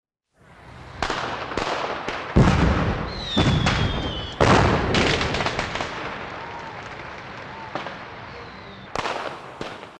Звуки фейерверков и петард